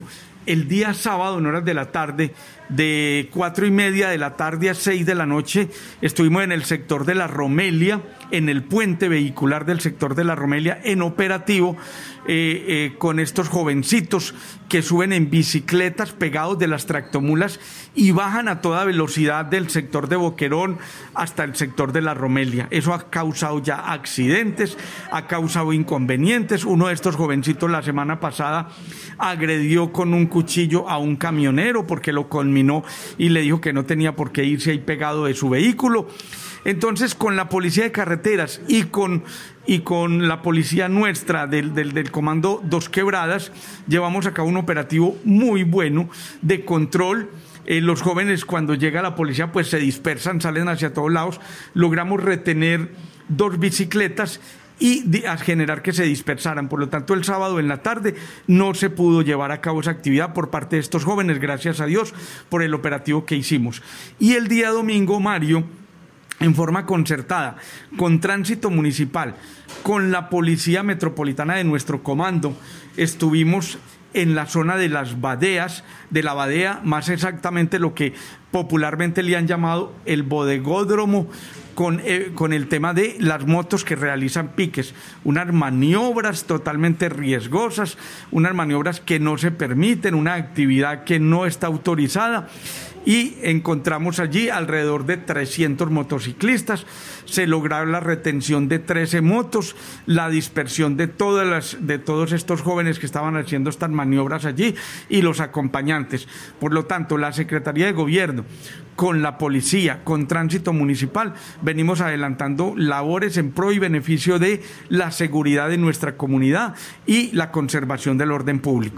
Comunicado-614-Sec-Gobierno-Juan-Carlos-Sepulveda.mp3